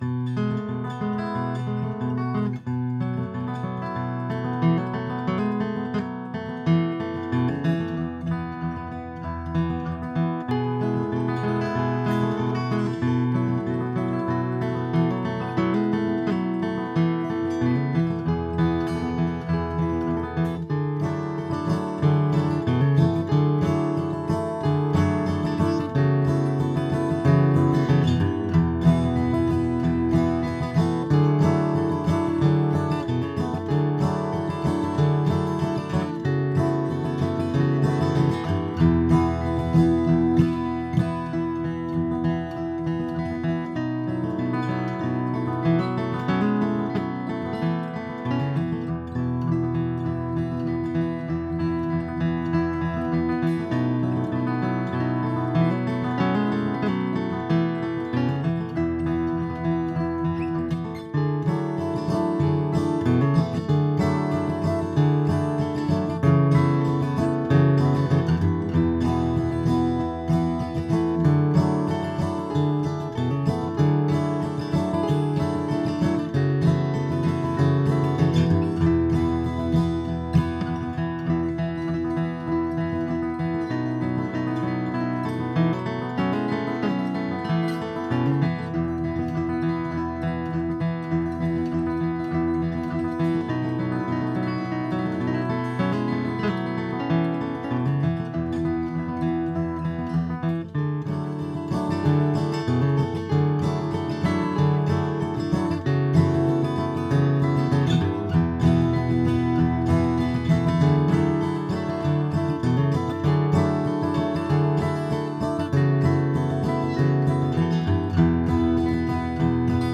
Wie immer konnte ich mich nicht beherrschen, daher sind es zwei Gitarren.
Ich habe die Kanäle im Pan aber recht weit auseinandergelegt. Diejenige die anfängt, wird durch eines der Rode-Presets gespielt, die andere durch ein Shure-sm57-Preset. Der DI-Out des ToneDexter ging direkt in den Eingang des Aufnahmegeräts, es ist also der reine Geräteklang, wie er aus der roten Kiste herauskommt.
Wenn man es nicht dazugesagt bekommt, meint man nicht, daß das ein Abnehmer ist.